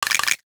NOTIFICATION_Rattle_12_mono.wav